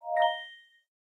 Category: Message Ringtones